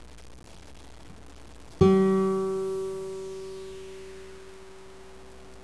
Tuning the Guitar
4. The fourth string sounds like
string3.wav